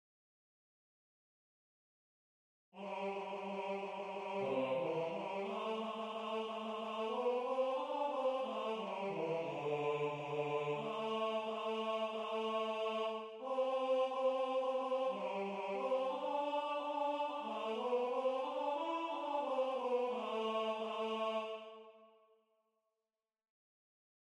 MP3 rendu voix synth.
Tenor 2